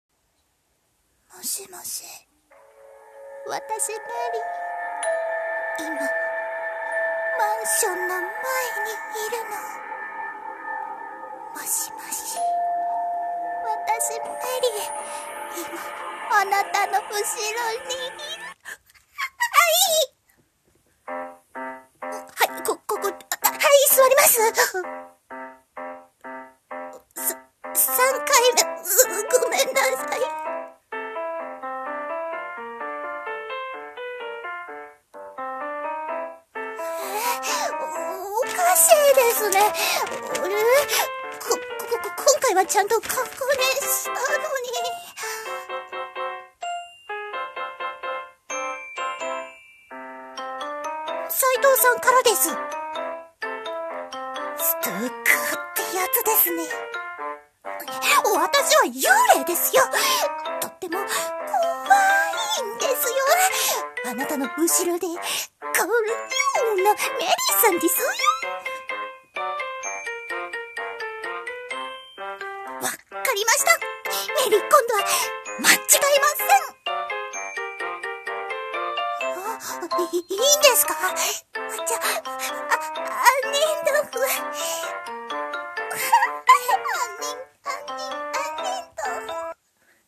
ギャグ声劇】真夏のメリーさん